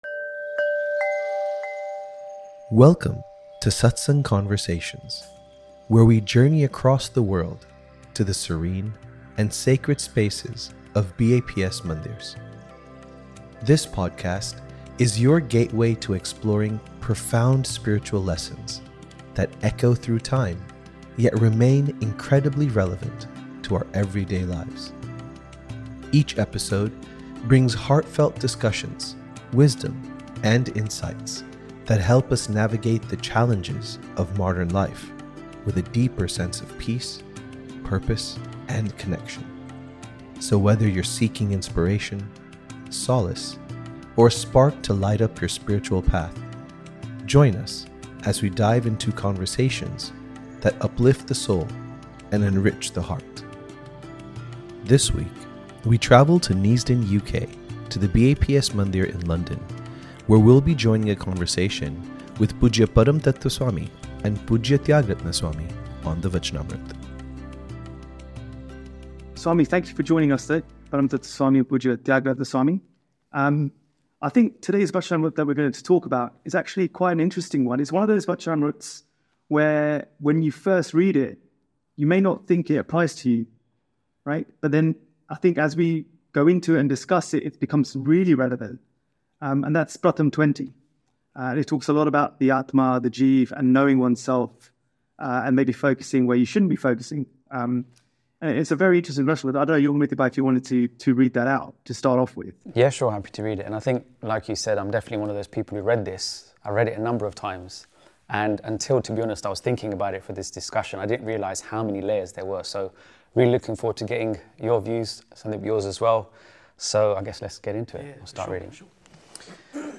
In this special episode, we travel to the iconic BAPS Shri Swaminarayan Mandir in Neasden, UK